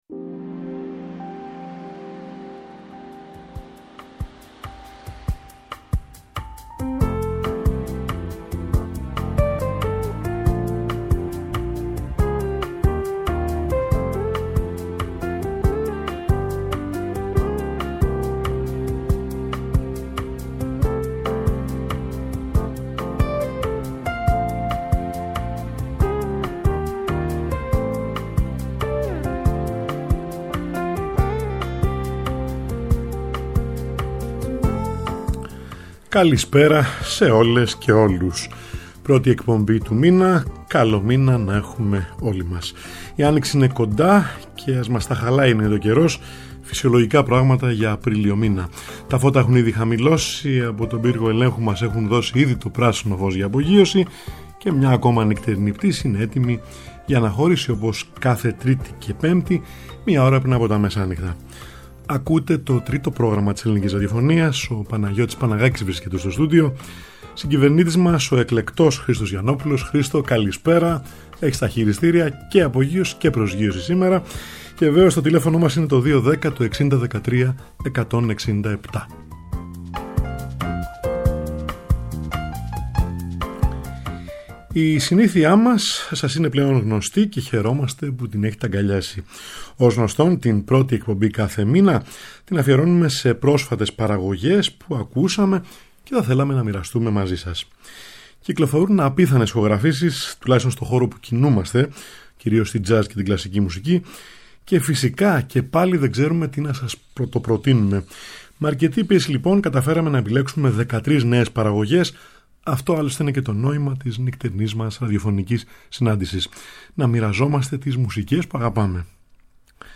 συμφωνικά έργα
η ατμοσφαιρική τρομπέτα